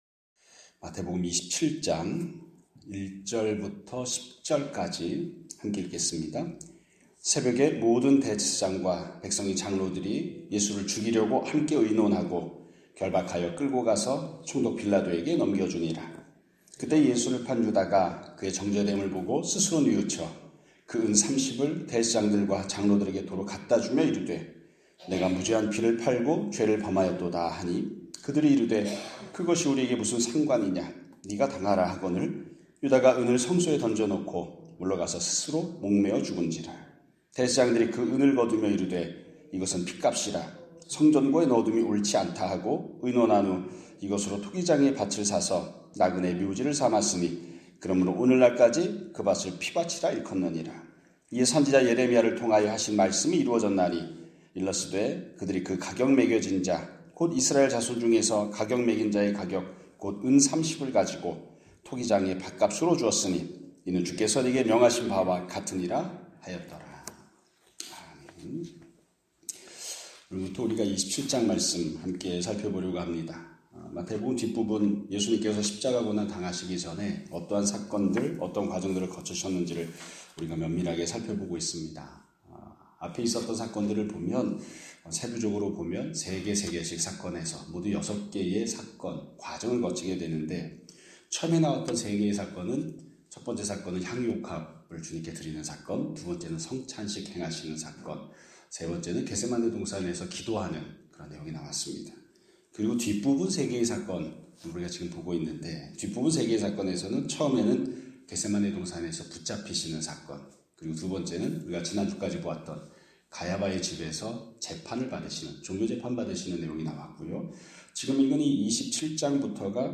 2026년 4월 13일 (월요일) <아침예배> 설교입니다.